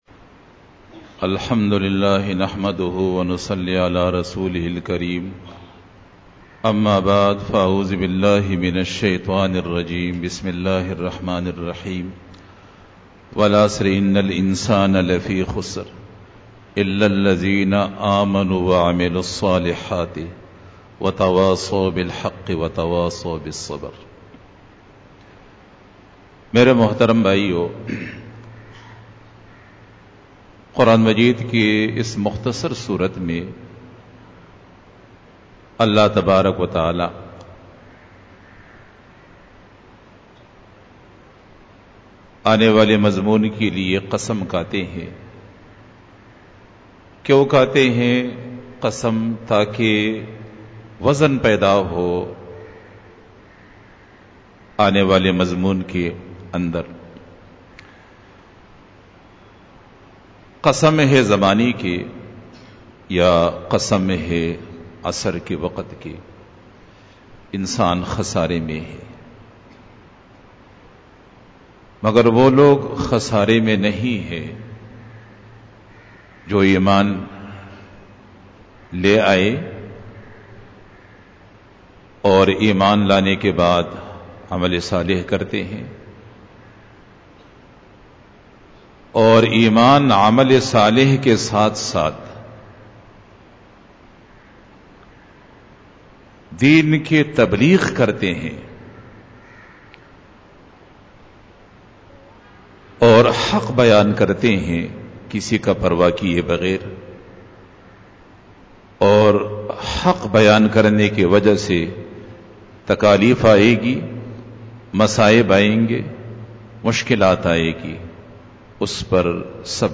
Khitab-e-Jummah 2018